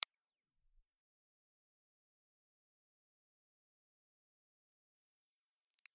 Omnidirectional
Speech (male) recorded with the Universal Handi-Mike 200A.